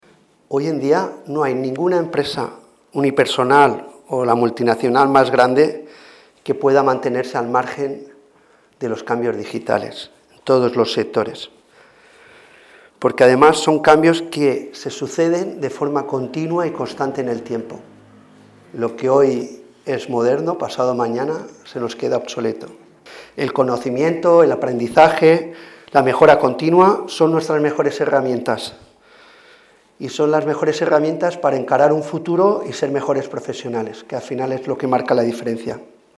declaraciones-del-alcalde-juan-antonio-amengual.mp3